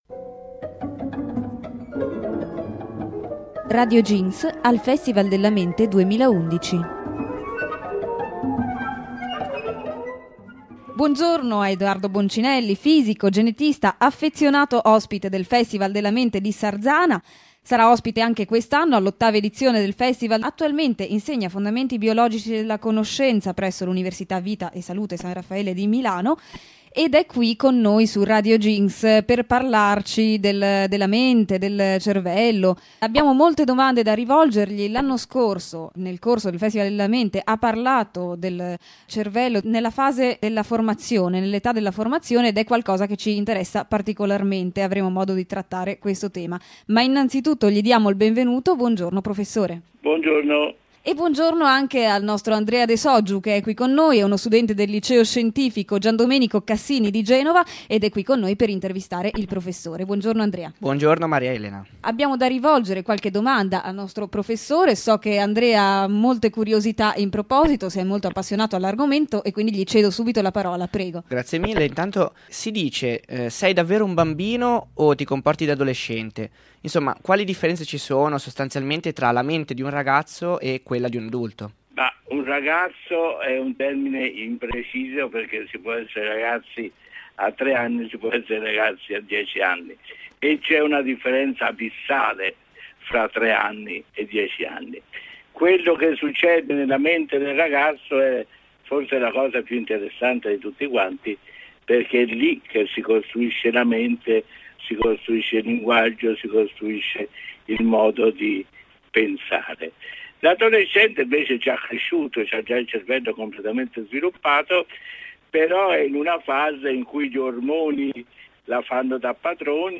Intervista a Edoardo Boncinelli